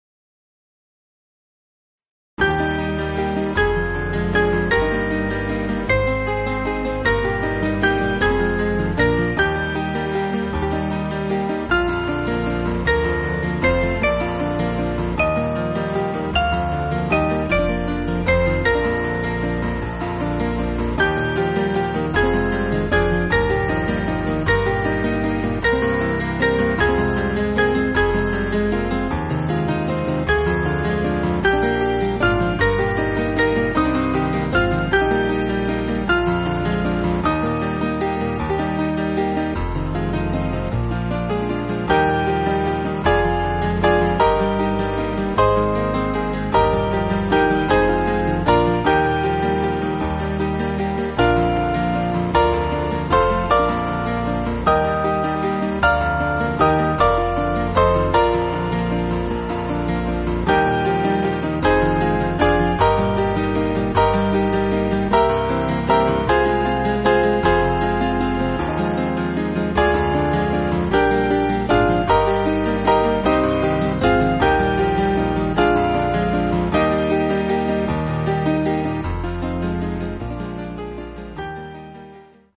Small Band
(CM)   6/Eb